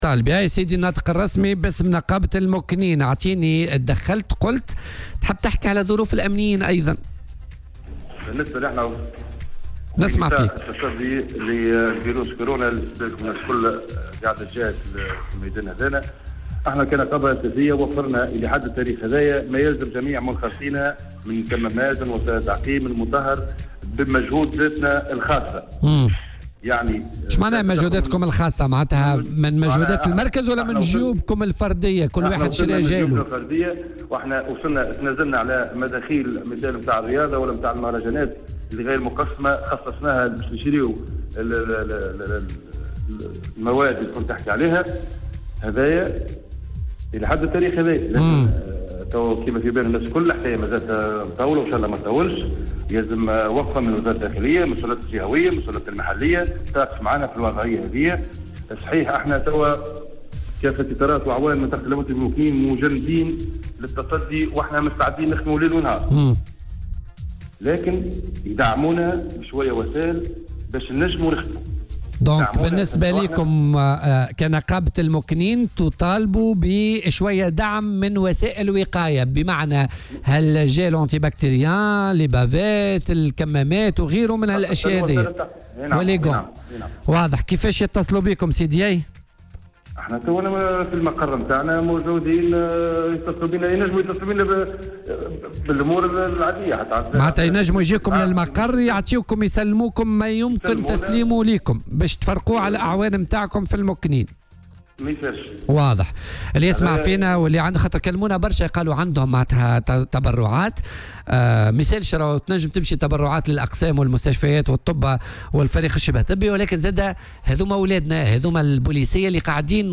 في مداخلة له اليوم على "الجوهرة أف أم"